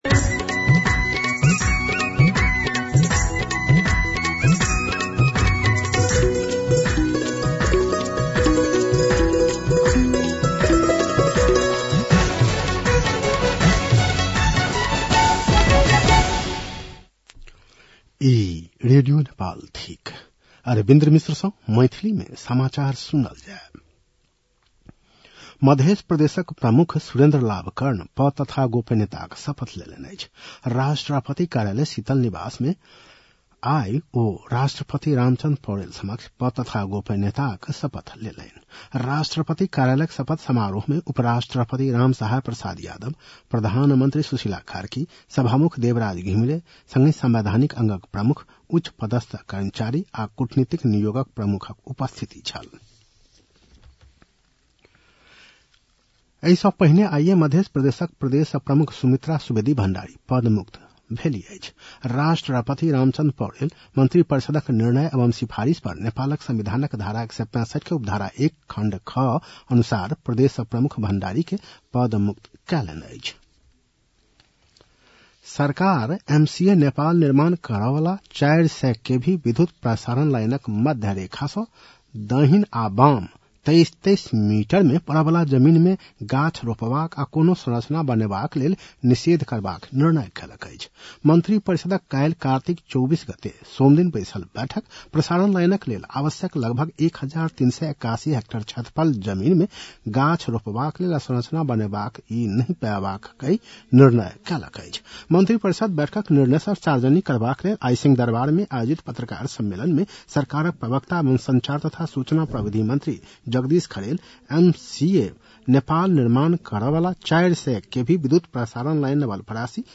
मैथिली भाषामा समाचार : २५ कार्तिक , २०८२